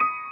piano82.ogg